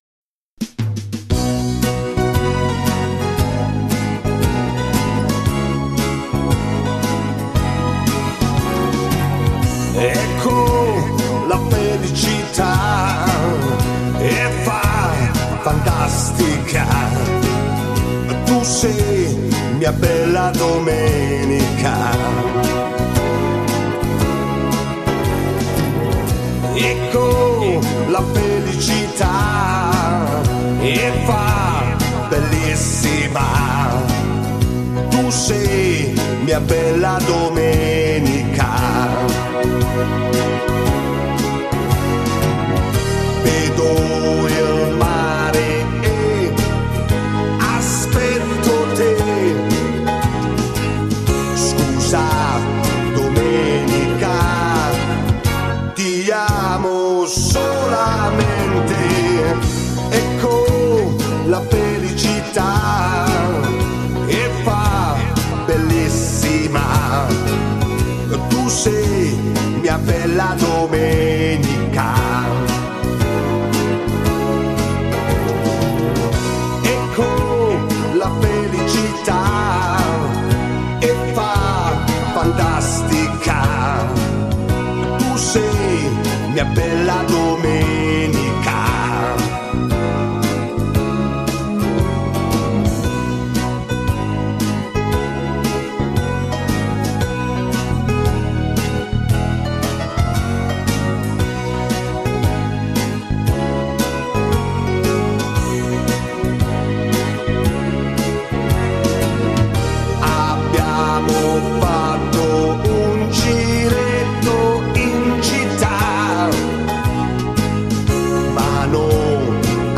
09 Foxtrot